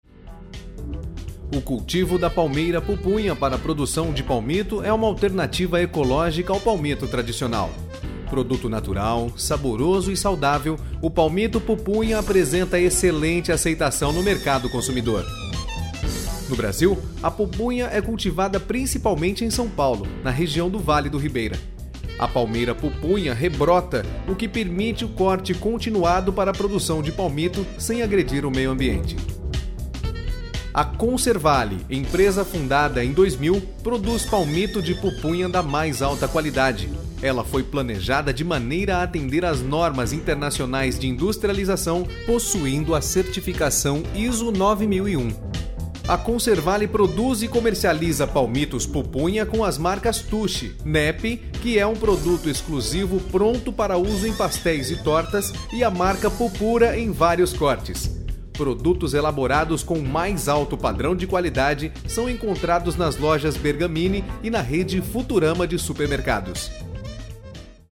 It can be fun, sexy, professional or smooth depending on the type of recording.
Sprechprobe: eLearning (Muttersprache):